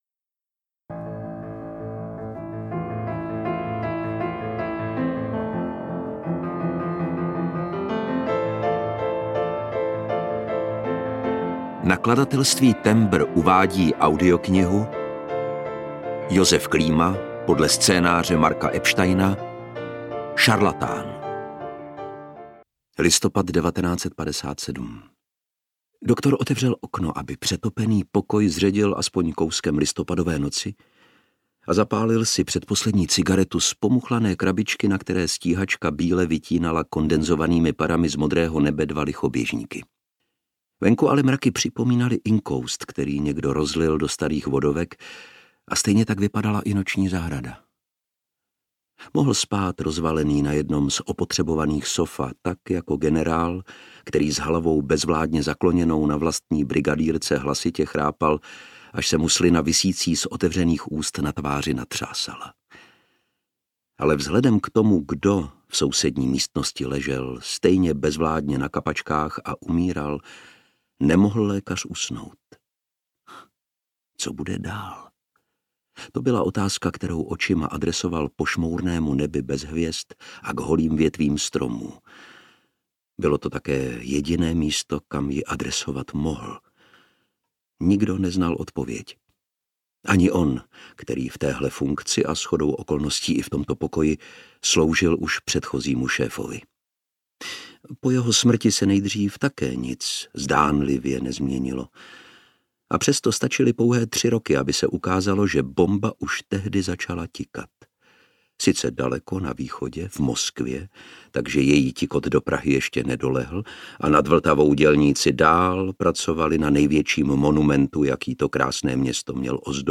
Šarlatán audiokniha
Ukázka z knihy
• InterpretLukáš Hlavica